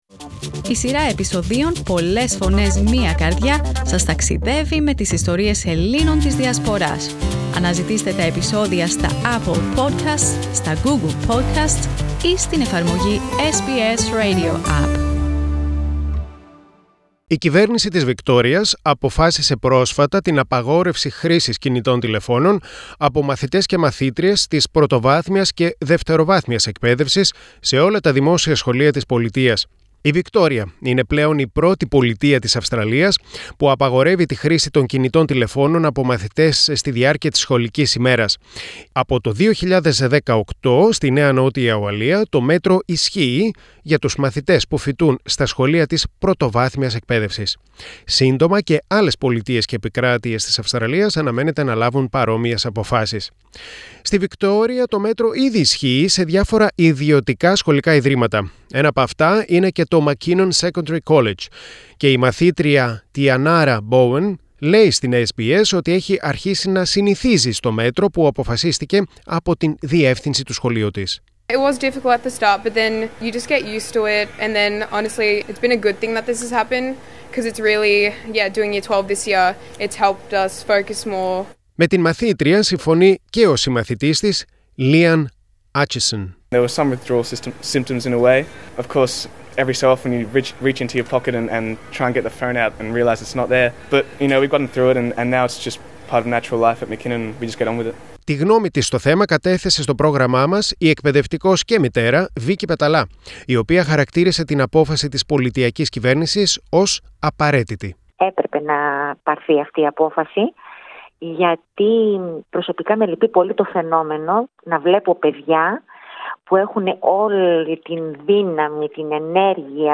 Greek Australian educators talk about the mobile phone ban in Victoria